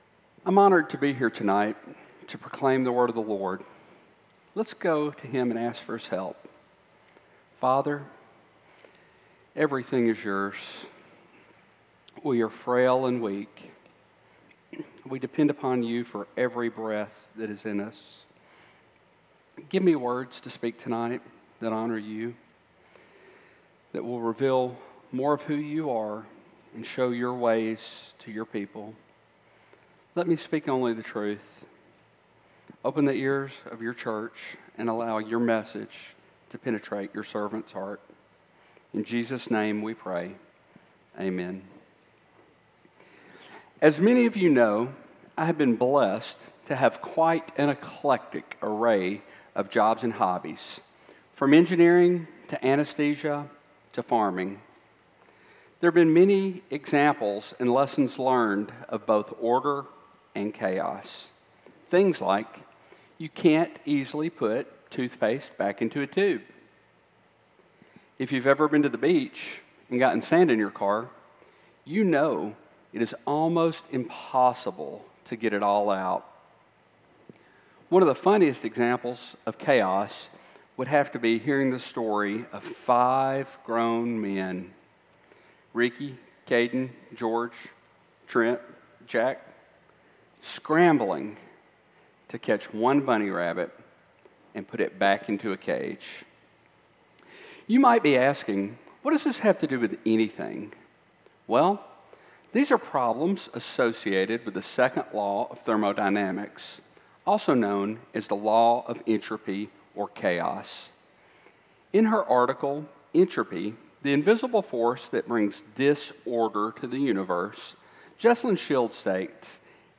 CCBC Sermons Psalm 119:33-40 Jun 15 2025 | 00:33:33 1x 00:00 / 00:33:33 Subscribe Share Apple Podcasts Spotify Overcast RSS Feed Share Link Embed